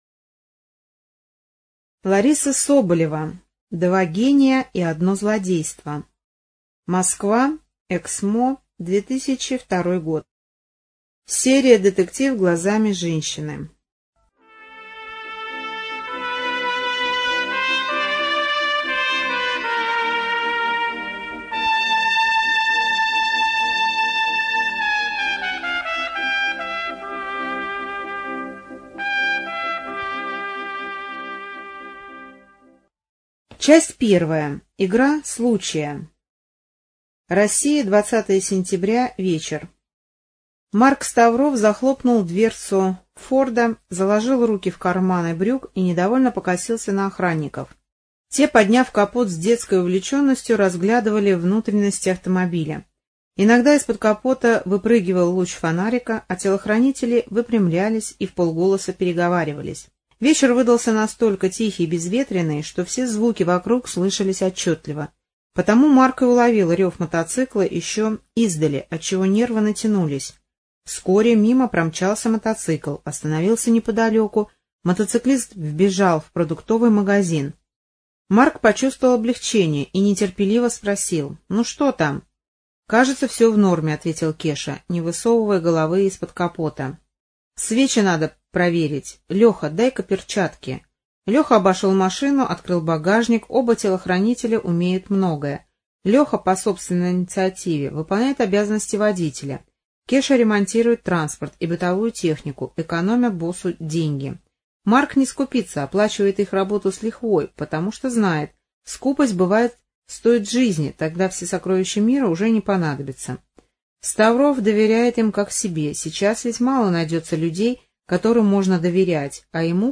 Студия звукозаписиТверская библиотека для слепых имени Михаила Ивановича Суворова